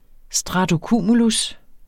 Udtale [ sdʁɑdoˈkuˀmulus ]